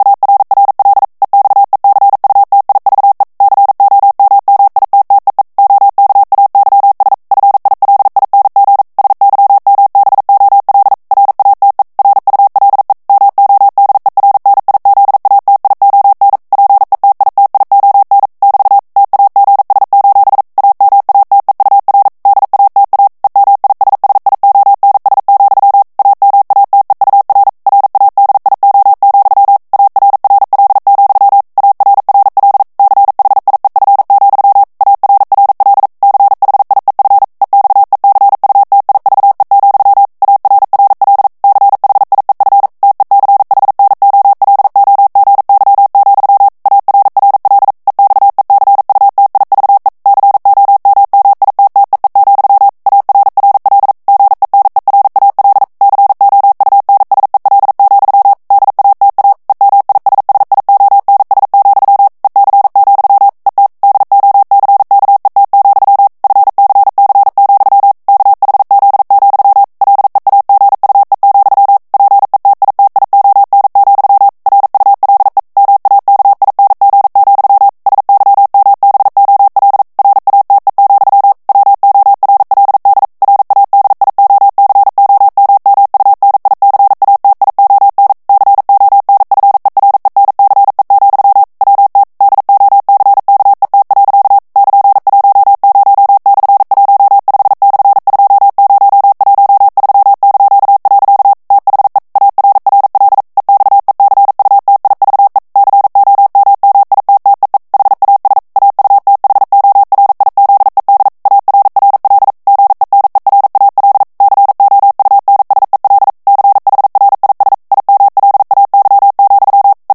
CODE MORSE-TEXTES EN CLAIR
ANGLAIS_texte_clair_3_vitesse_40_mots_minute.mp3